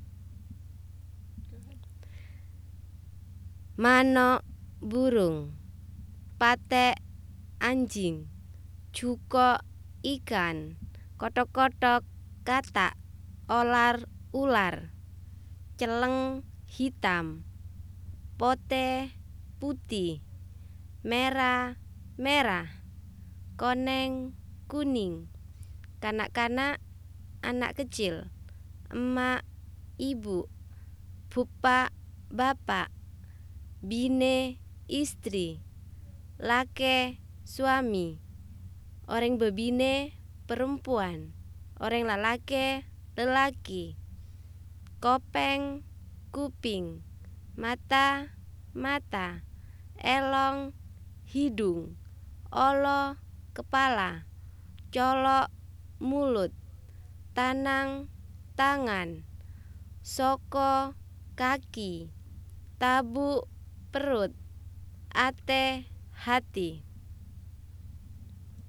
recording of assorted animals)